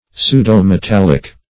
Search Result for " pseudo-metallic" : The Collaborative International Dictionary of English v.0.48: Pseudo-metallic \Pseu`do-me*tal"lic\, a. [Pseudo- + metallic.]